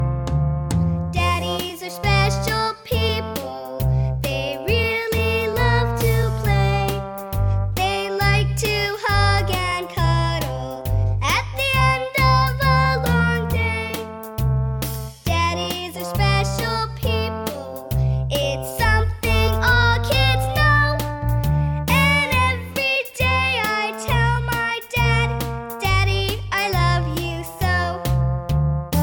Genre: Alternative & Punk.